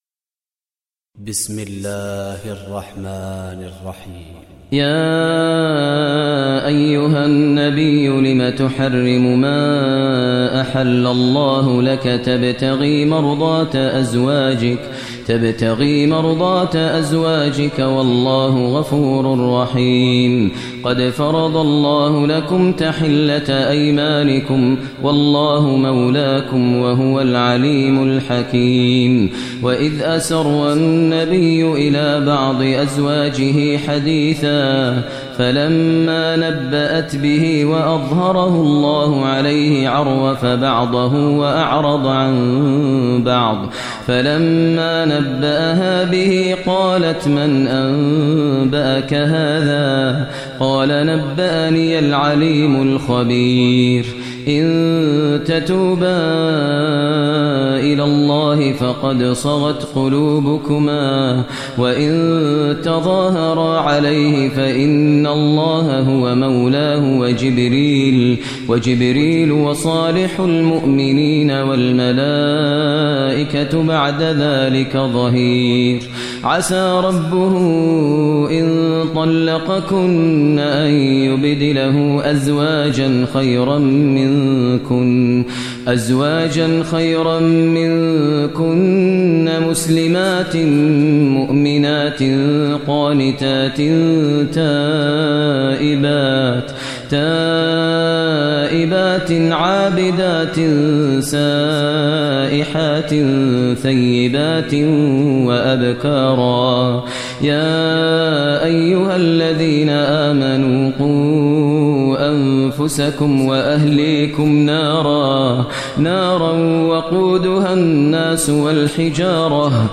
Surah Tahrim Recitation by Sheikh Maher al Mueaqly
Surah Tahrim, listen online mp3 tilawat / recitation in the voice of Imam e Kaaba Sheikh Maher al Mueaqly.